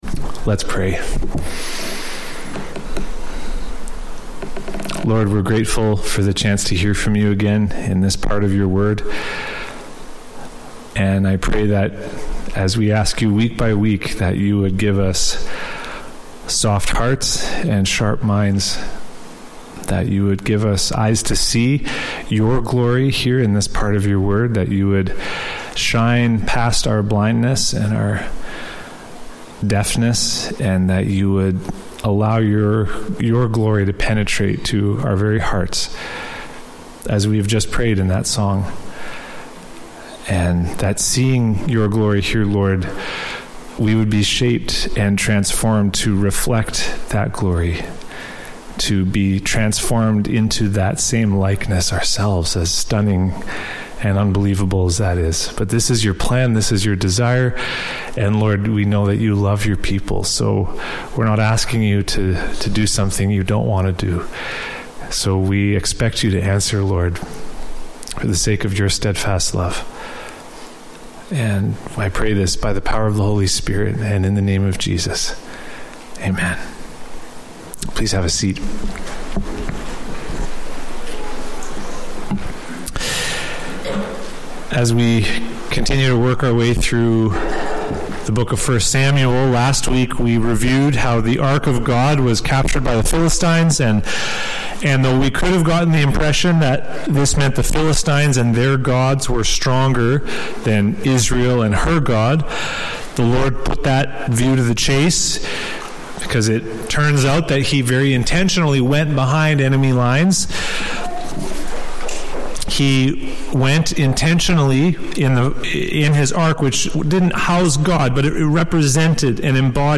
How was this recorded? What We Worship – Emmanuel Baptist Church